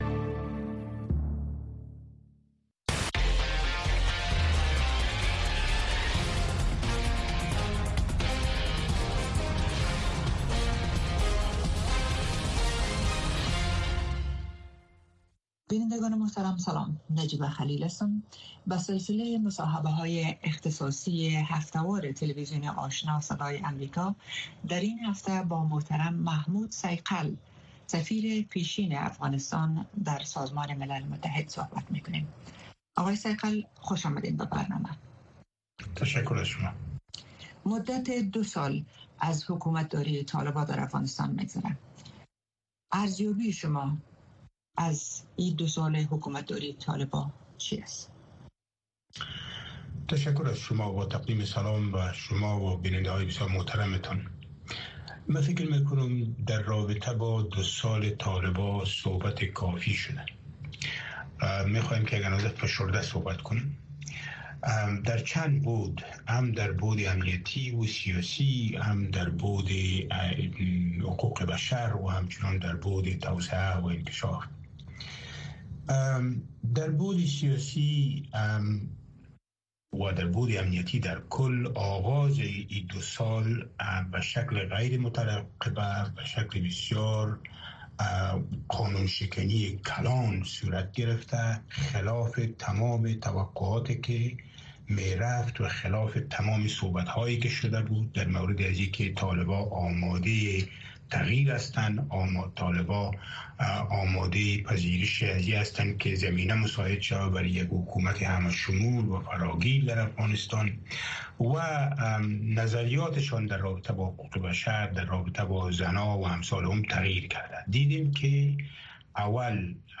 گفتگو‌های ویژه با مسوولان، مقام‌ها، کارشناسان و تحلیلگران در مورد مسایل داغ افغانستان و جهان را هر شنبه در نشرات ماهواره‌ای و دیجیتلی صدای امریکا دنبال کنید.